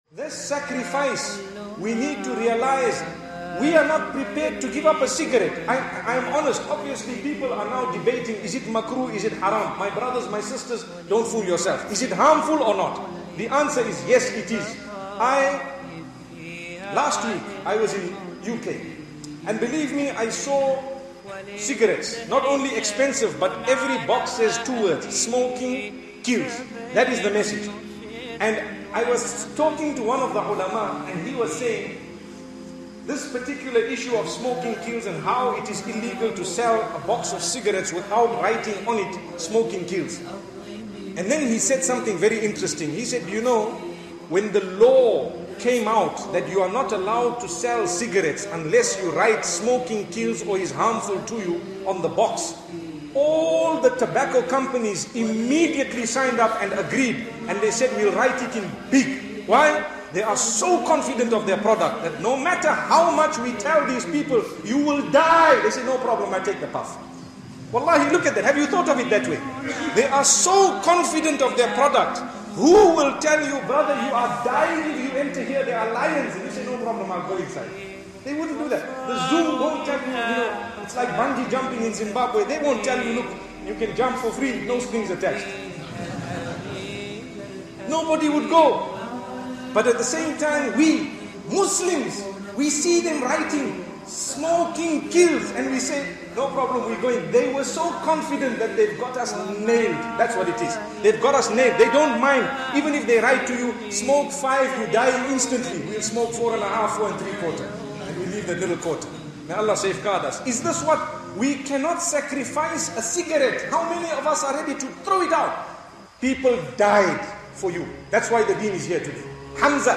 What have you Sacrificed for Islam... an emotional eye opening reminder for us all by Mufti Menk.
In one of the most emotionally powerful lectures featured on The Deen Show, the speaker delivers a soul-shaking reminder about sacrifice: the companions of the Prophet Muhammad (peace be upon him) gave their very lives so that Islam could reach us today, yet many Muslims today cannot even give up a cigarette, wake up for Fajr prayer, or abandon pornography.